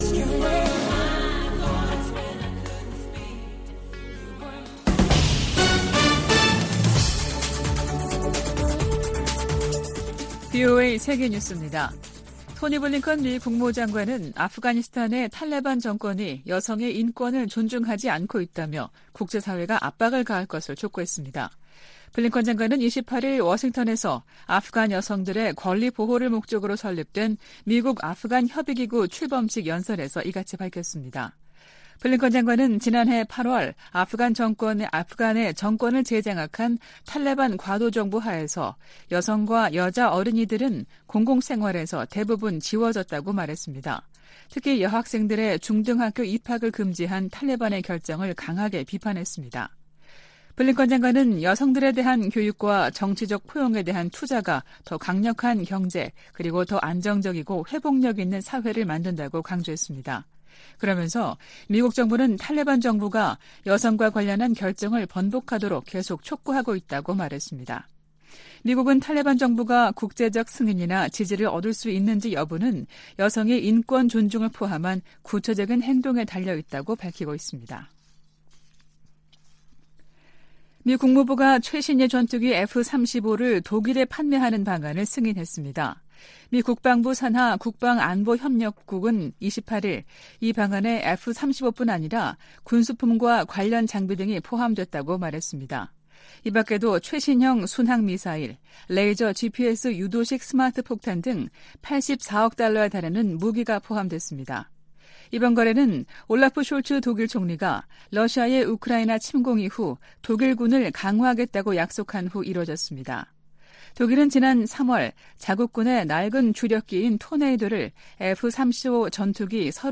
VOA 한국어 아침 뉴스 프로그램 '워싱턴 뉴스 광장' 2022년 7월 30일 방송입니다. 미 국무부는 김정은 국무위원장의 전승절 기념행사 연설에 직접 반응은 내지 않겠다면서도 북한을 거듭 국제평화와 안보에 위협으로 규정했습니다. 핵확산금지조약(NPT) 평가회의에서 북한 핵 문제가 두 번째 주부터 다뤄질 것이라고 유엔 군축실이 밝혔습니다.